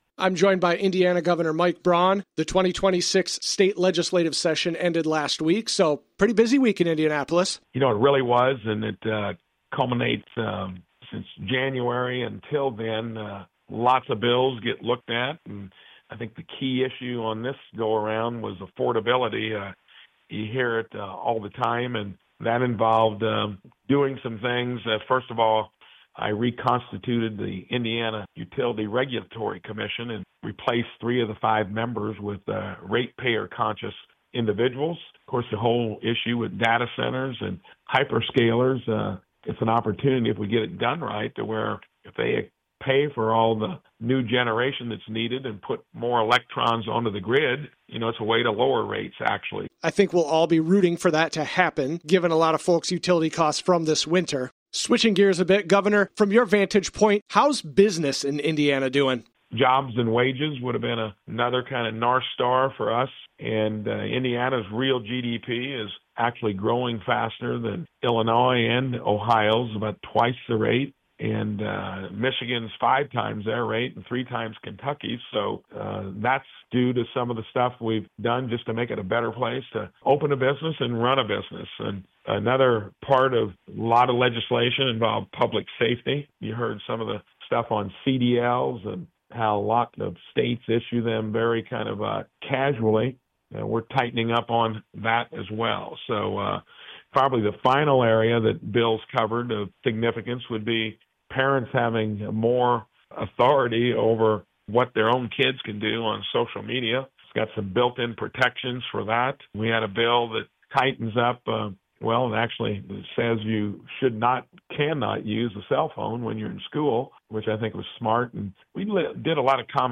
Governor Braun called in to the WIKY Afternoon Show today to discuss new legislation that came out of the 2026 legislative session.